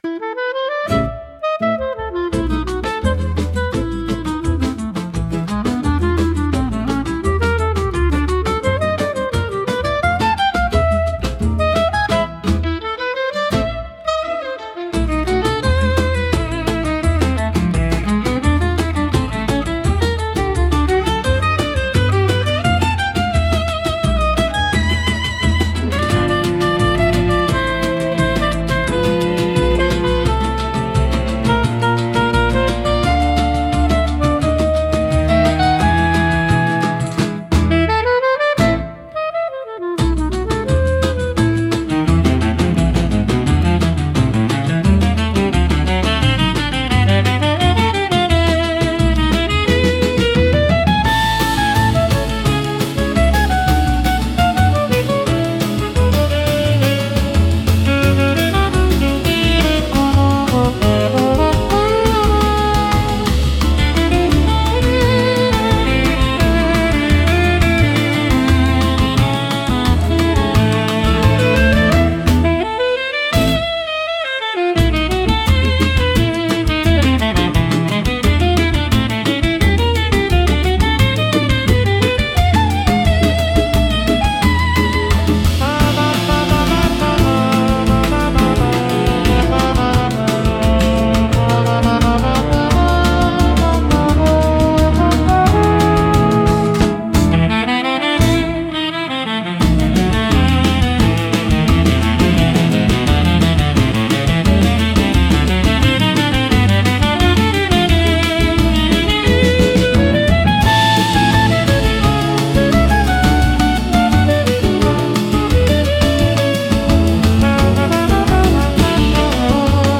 instrumental 6